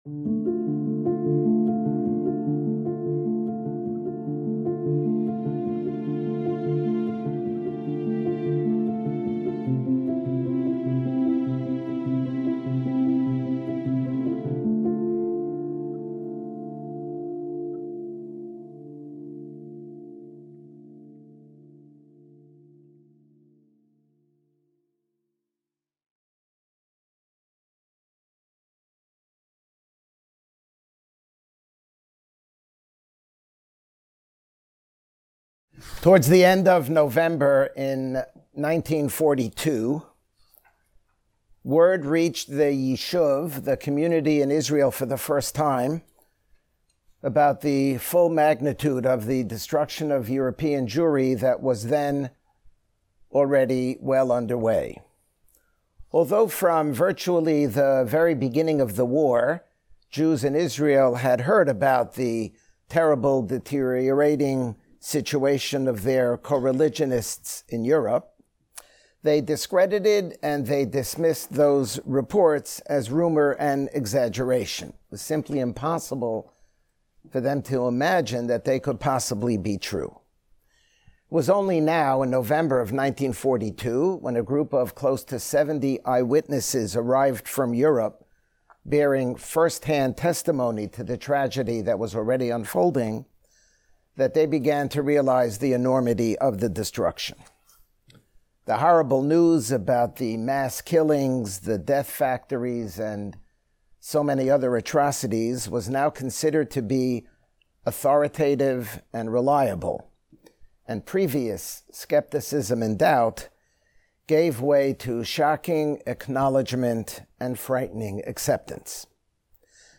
As horrible as many of the events covered in previous lectures were, none compare to the Holocaust. In this lecture